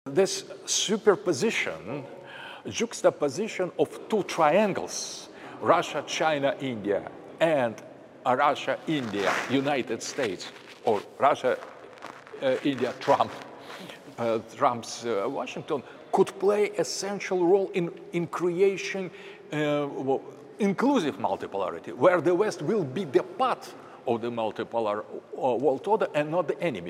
Alexandr Dugin - RT interview Can the West be the part of multipolar world and not the enemy?